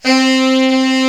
Index of /90_sSampleCDs/Giga Samples Collection/Sax/HARD + SOFT
TENOR HARD.1.wav